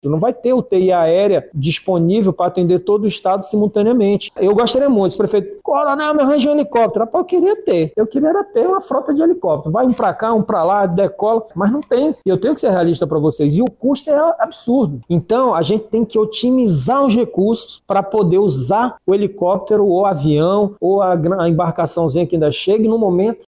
Segundo o coronel, caso o Amazonas enfrente uma nova estiagem, não será possível atender todos os municípios com o deslocamento de enfermos em aeronaves.